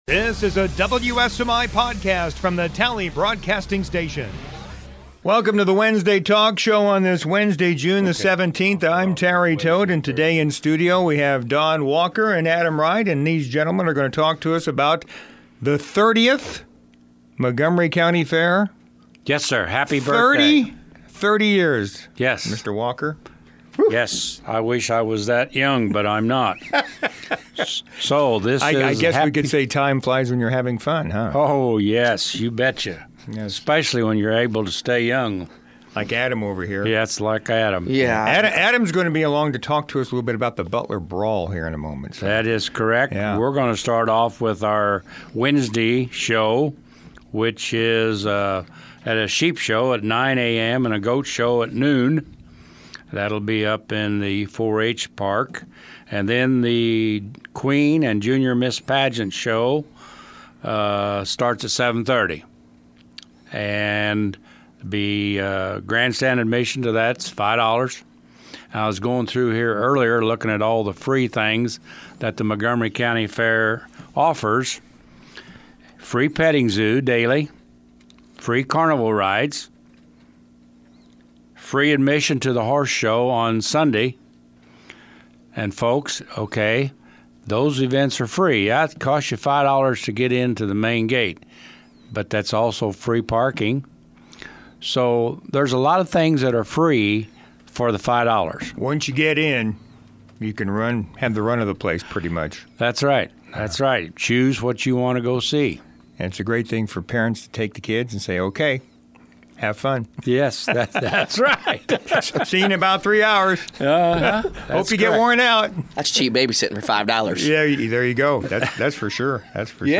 Wednesday Talk Show